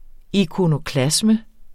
Udtale [ ikonoˈklasmə ]